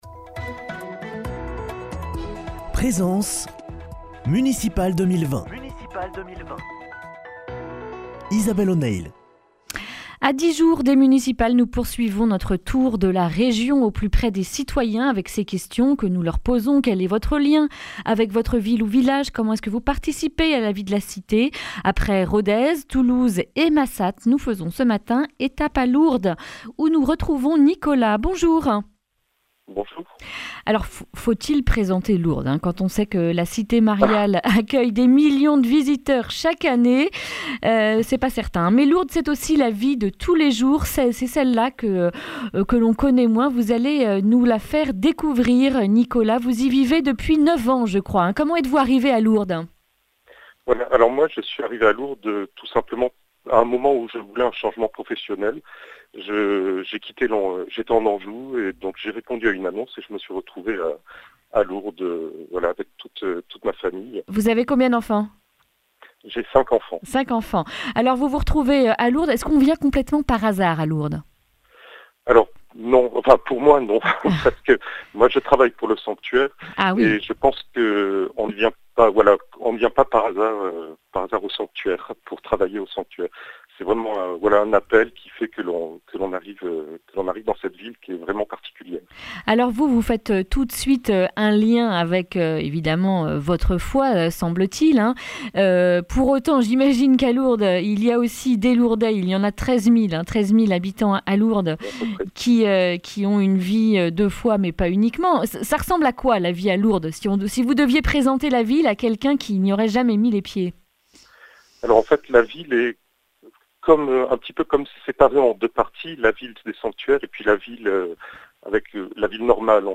jeudi 5 mars 2020 Le grand entretien Durée 11 min